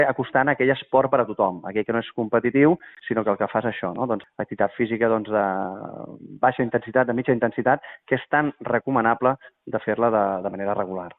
El regidor d’Esports de l’Ajuntament de Calella, Manel Vicente, fa una crida i anima a participar de les propostes.